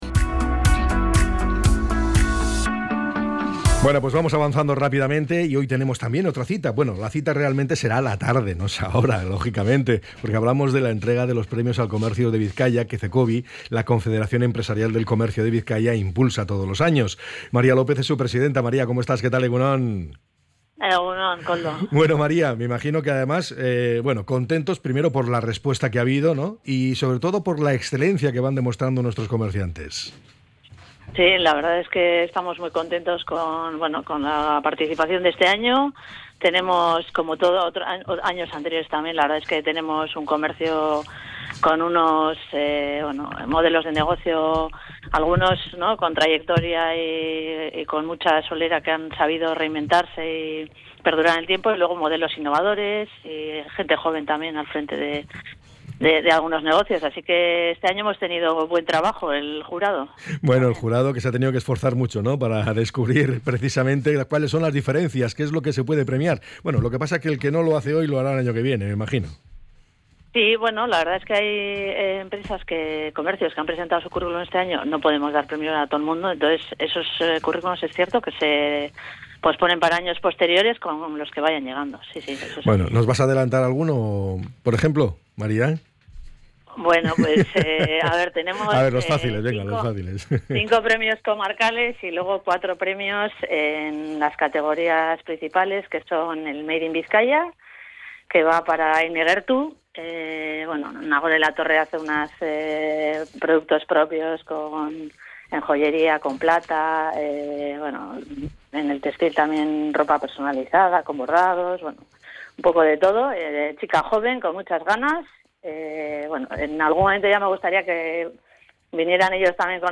ENTREV.-CECOBI.mp3